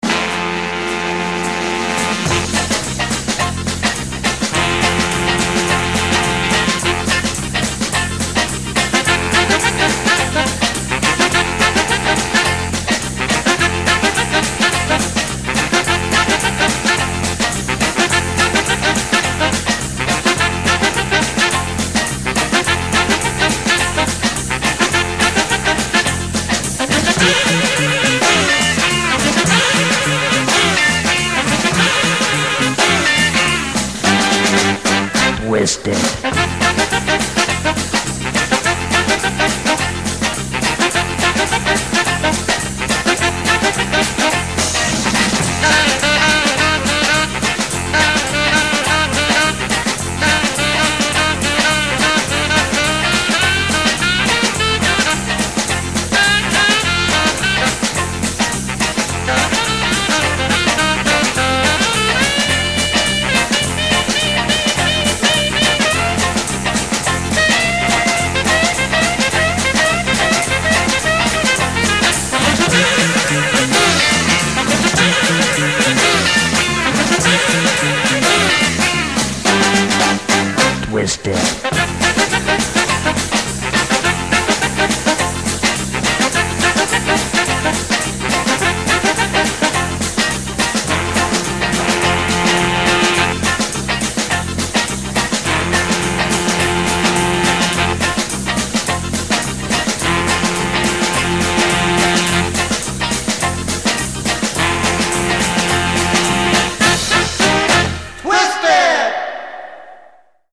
INSTR